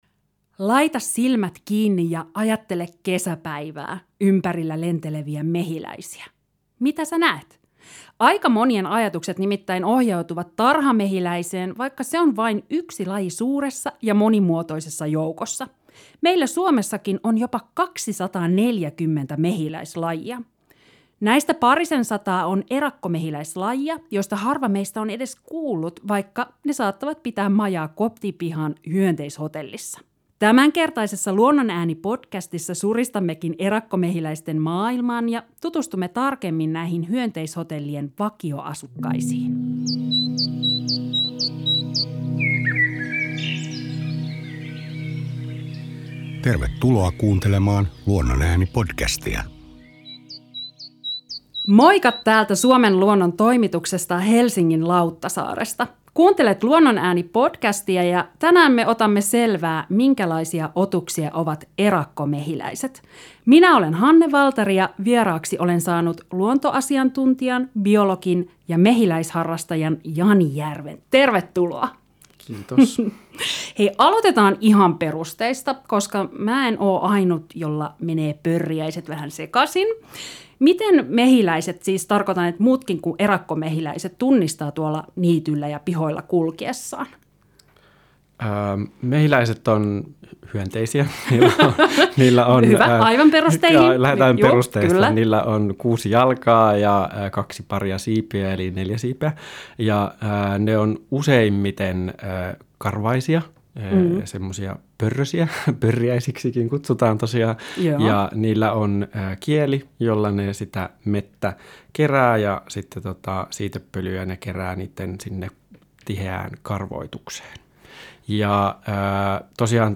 Studiossa
luontoasiantuntija, biologi ja mehiläisharrastaja